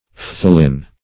Search Result for " phthalin" : The Collaborative International Dictionary of English v.0.48: Phthalin \Phthal"in\, n. (Chem.)